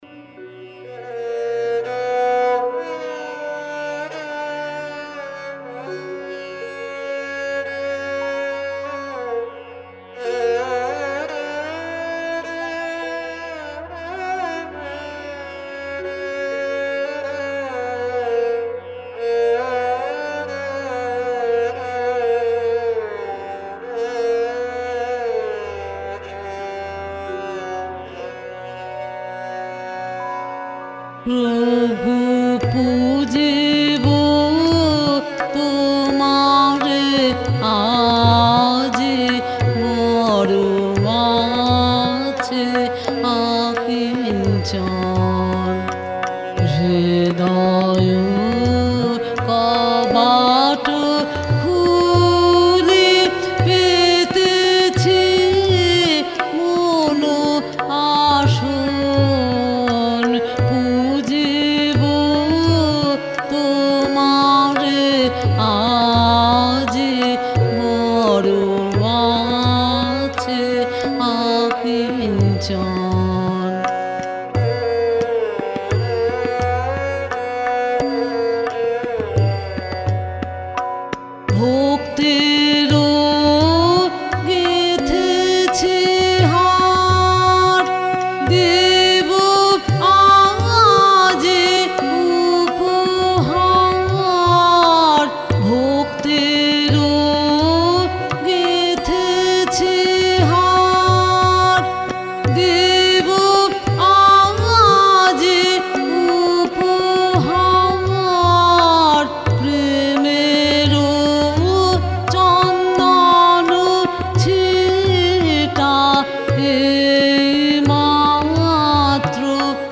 রামকেলী। ঝাঁপতাল
সুরকার: শতগান, গ্রন্থে গানটি সুর হিসেবে উল্লেখ আছে হিন্দুস্থানী।
পর্যায়:  ব্রহ্মসঙ্গীত
লয়: ঈষৎ দ্রুত